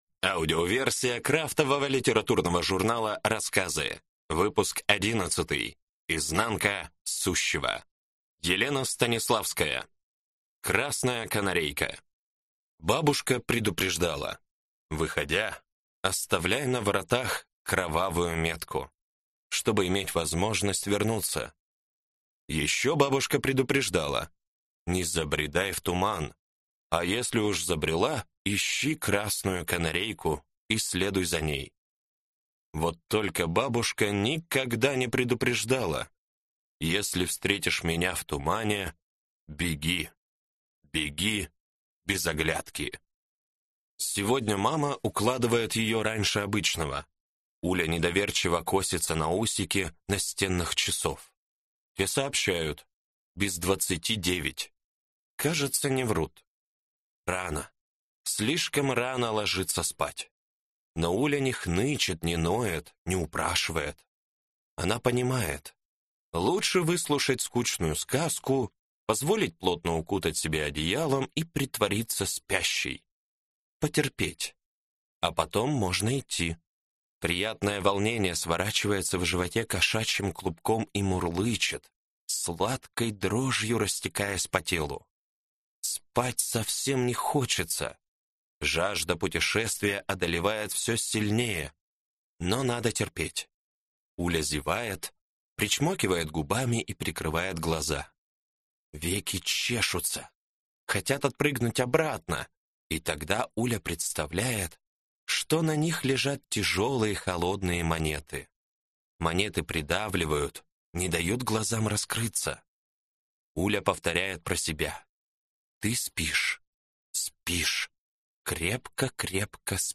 Аудиокнига Рассказы 11. Изнанка сущего | Библиотека аудиокниг